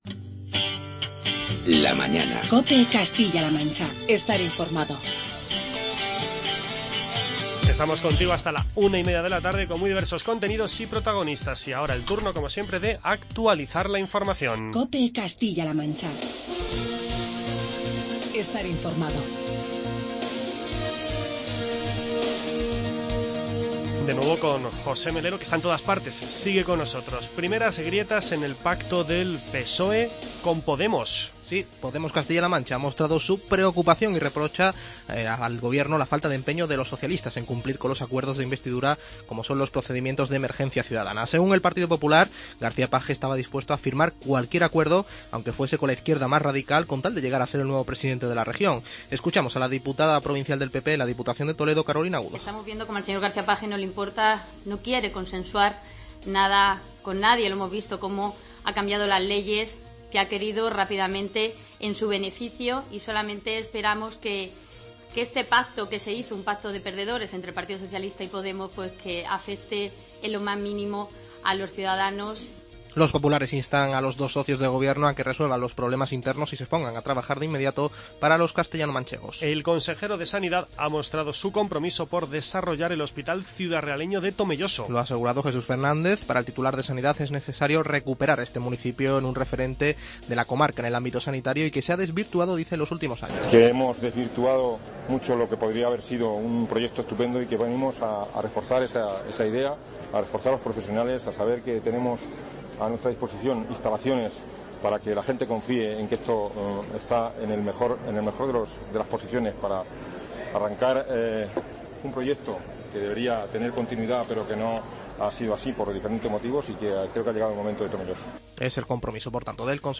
Conversamos con Carmen Fúnez, senadora del PP por Ciudad Real, y con Pedro García Hidalgo, concejal de Festejos del Ayuntamiento de Cuenca